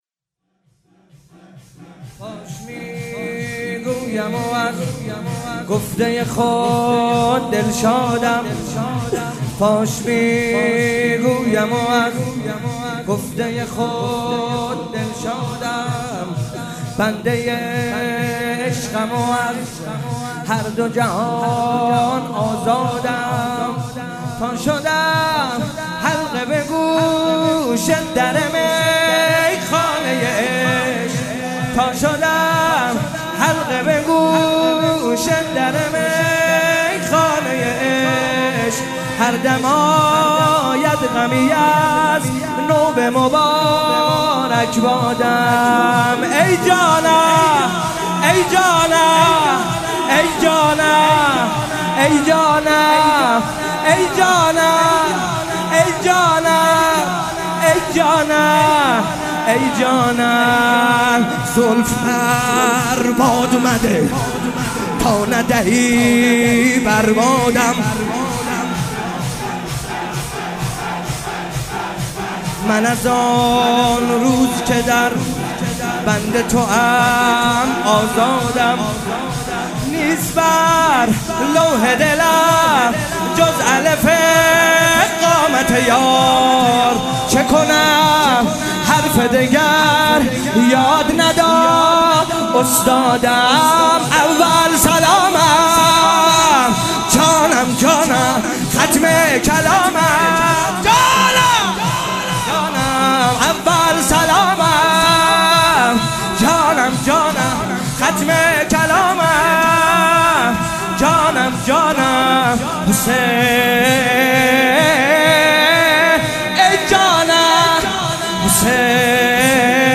شب دوم محرم - ورود کاروان به کربلا
حسین طاهری
محرم 95 شور حسین طاهری مداحی